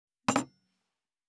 232,バタン,スッ,サッ,コン,ペタ,パタ,チョン,コス,カラン,ドン,チャリン,効果音,環境音,BGM,
コップ効果音厨房/台所/レストラン/kitchen物を置く食器
コップ